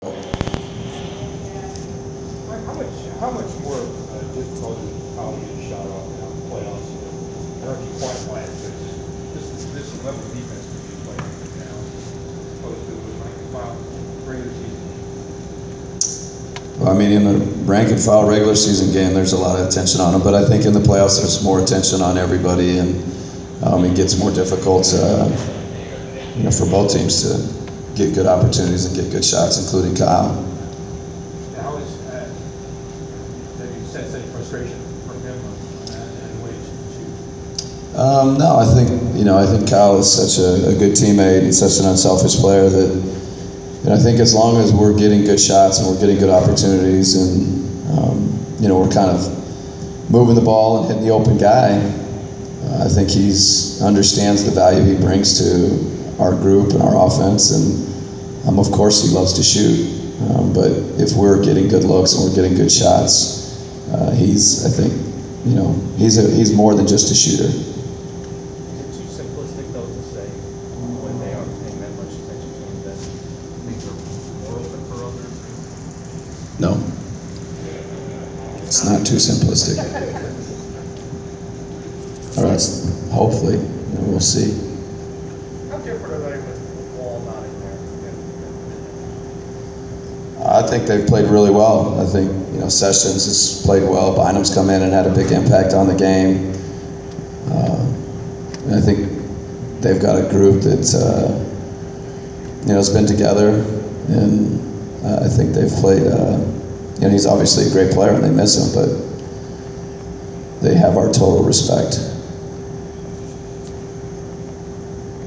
Inside the Inquirer: Atlanta Hawks’ head coach Mike Budenholzer pregame presser 5.13.15
The Sports Inquirer attended the pregame presser of Atlanta Hawks’ head coach Mike Budenholzer before his team’s home playoff contest against the Washington Wizards on May 13.